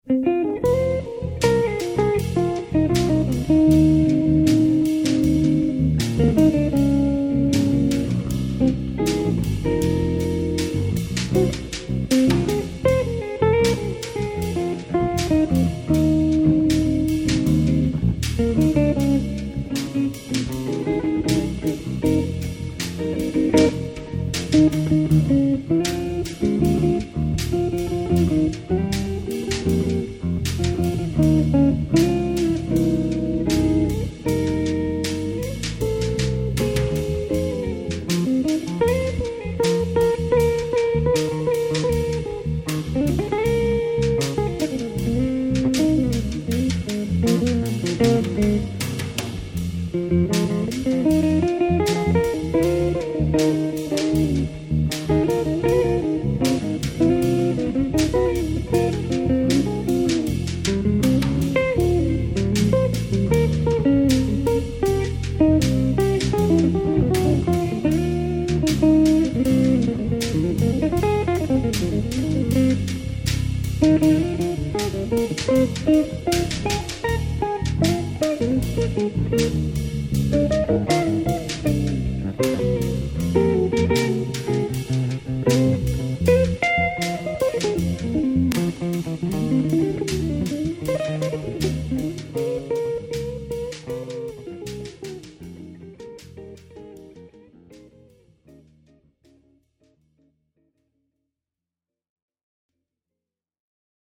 Jazz/Funk